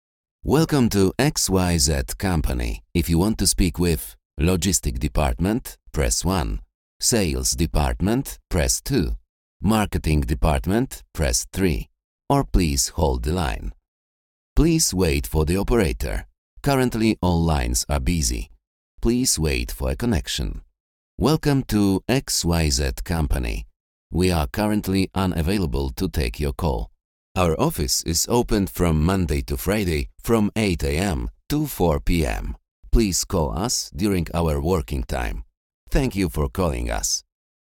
Mężczyzna 30-50 lat
Doświadczony lektor dubbingowy.
Zapowiedź telefoniczna w języku angielskim